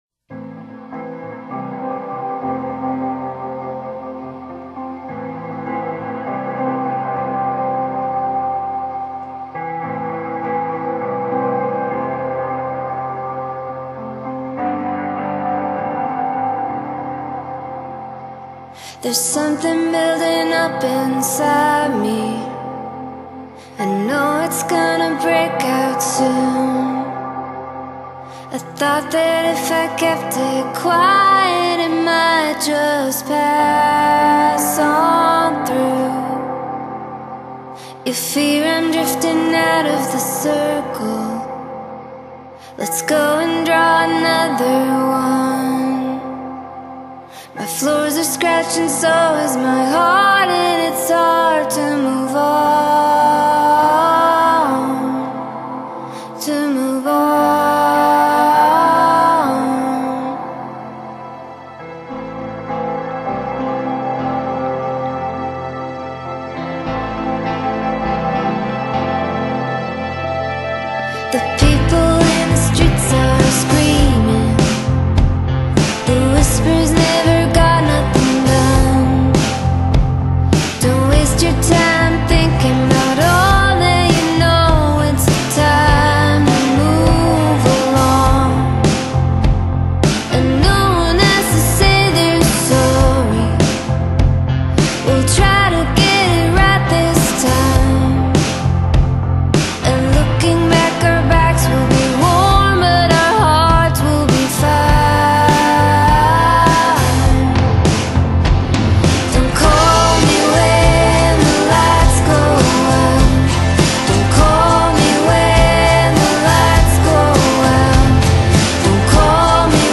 Indie Pop, Electropop | FLAC/ 37 min | 284.5MB + 5% Recovery